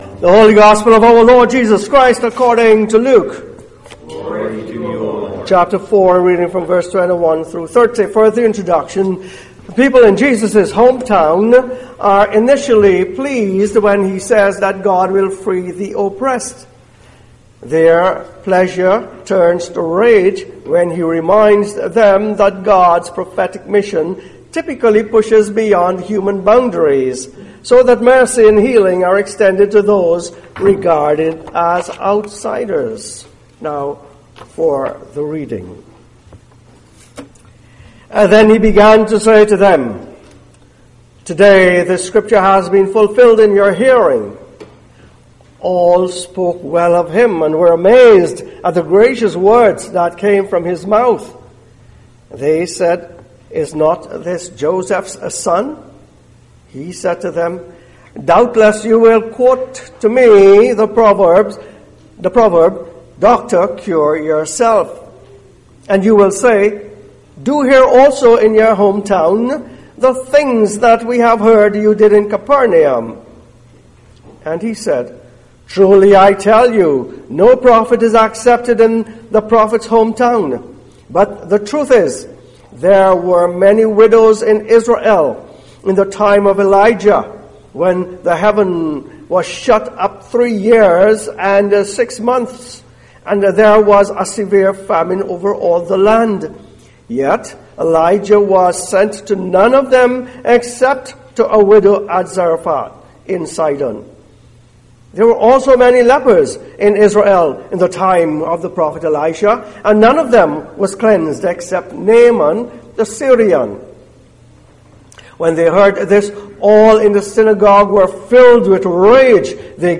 2019 Sermons Passage: 1 Corinthians 13:1-13 Service Type: Sunday Service « What Makes a Church Attractive?